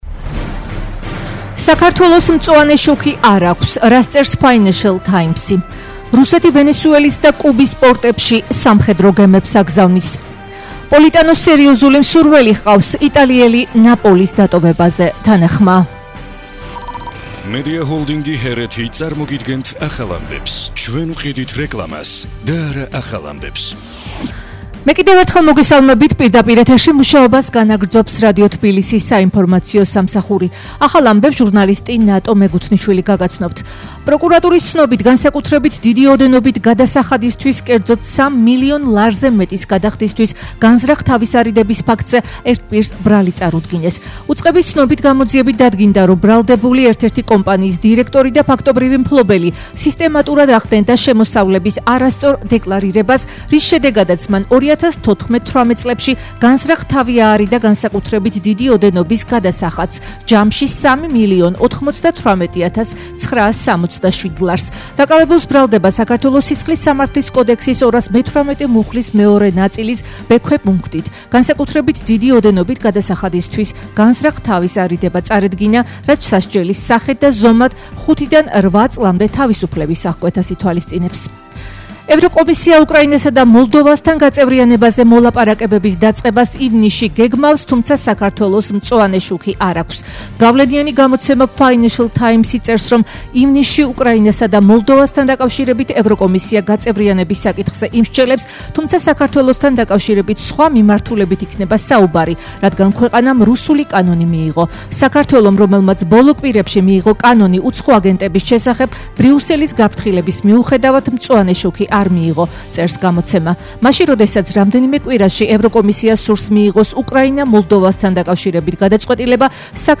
ახალი ამბები 15:00 საათზე